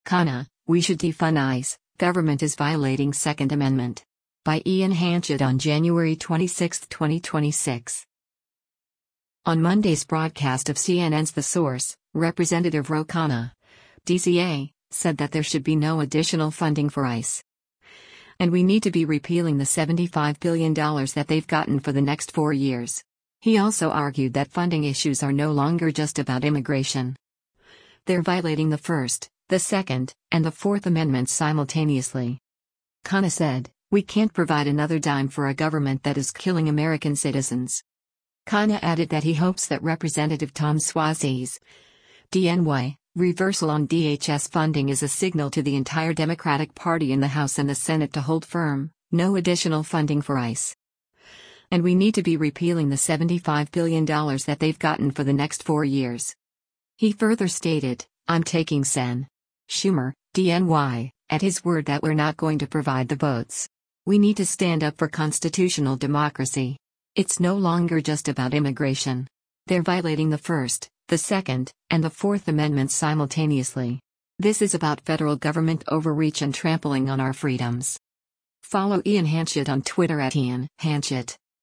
On Monday’s broadcast of CNN’s “The Source,” Rep. Ro Khanna (D-CA) said that there should be “no additional funding for ICE. And we need to be repealing the $75 billion that they’ve gotten for the next four years.”